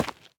Minecraft Version Minecraft Version snapshot Latest Release | Latest Snapshot snapshot / assets / minecraft / sounds / block / dripstone / step6.ogg Compare With Compare With Latest Release | Latest Snapshot
step6.ogg